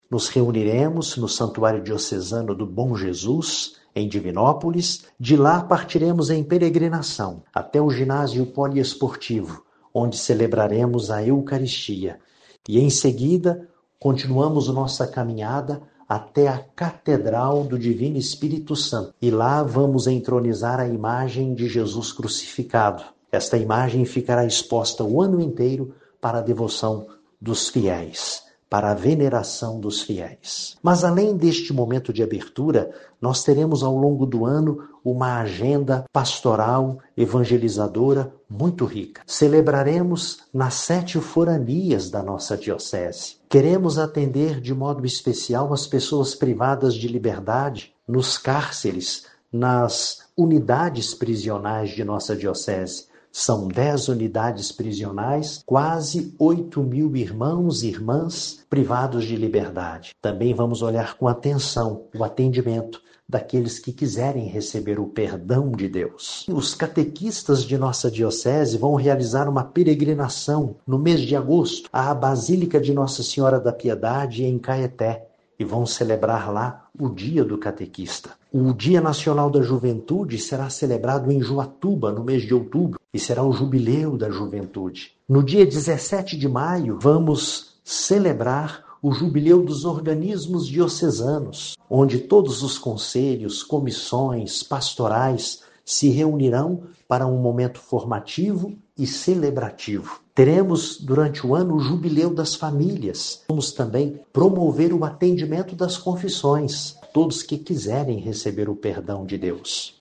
Mas Dom Geovane lembra que a programação do Jubileu seguirá ao longo de todo o ano de 2025: